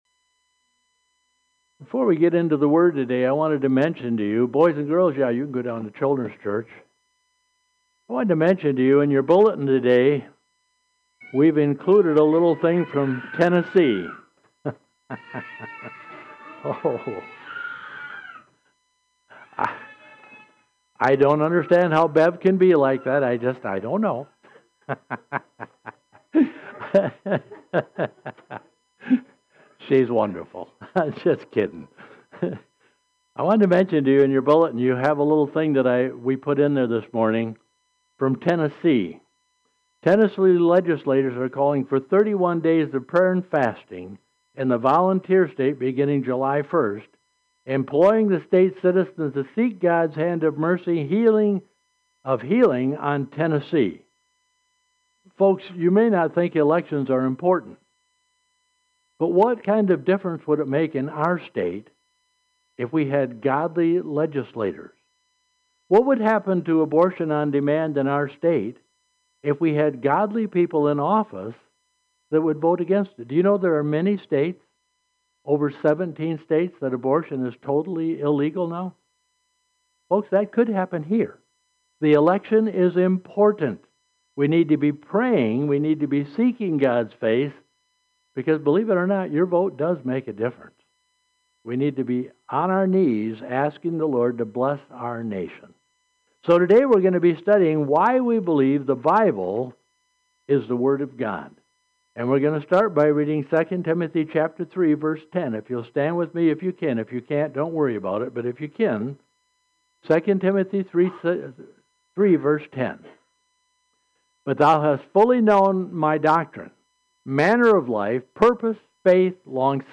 INTRODUCTION: Much of what I am preaching today is based on the foundation of a message preached by Dr. R. A Torrey in 1898.